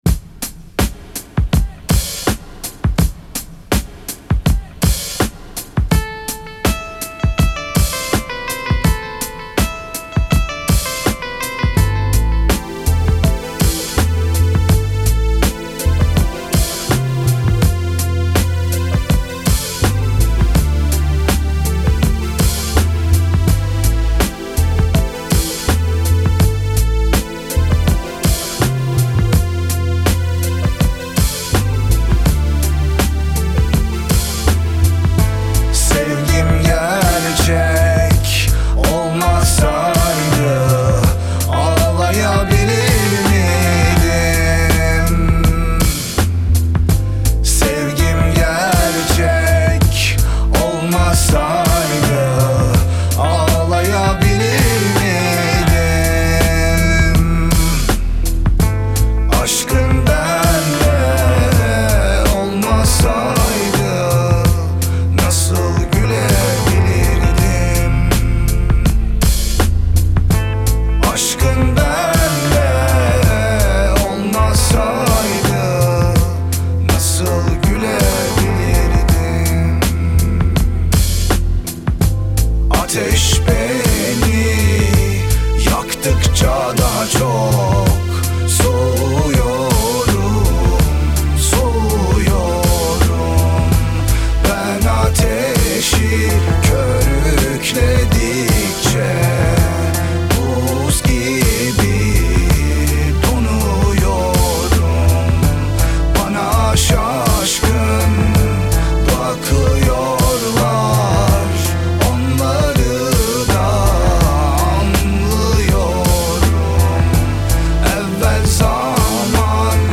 Трек размещён в разделе Турецкая музыка / Рэп и хип-хоп.